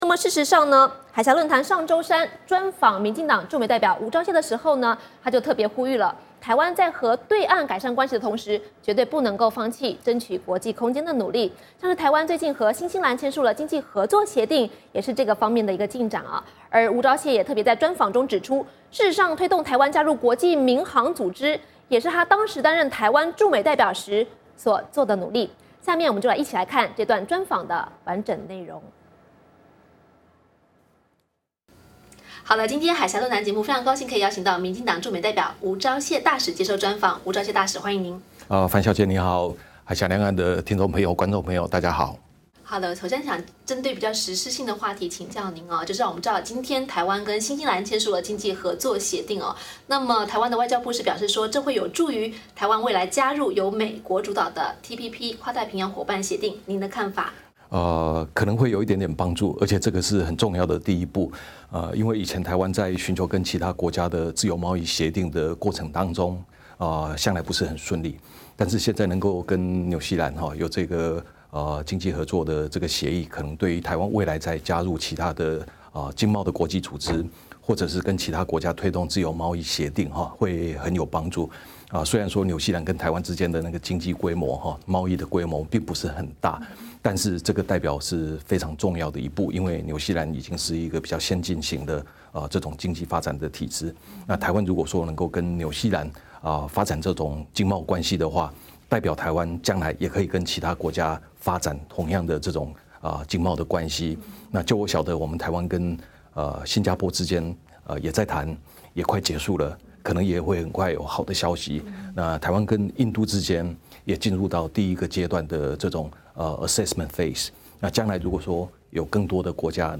海峡论谈:专访民进党驻美代表吴钊燮(完整版)
台湾民进党驻美代表吴钊燮7月10日在美国首都华盛顿邻近白宫旁的民进党驻美代表处接受美国之音海峡论谈节目专访，他谈到美国支持台湾参与国际民航组织ICAO、第五轮美中战略与经济对话、美国对台军售、民进党的两岸论述、并展望台湾明年的七合一选举和2016年的台湾总统大选。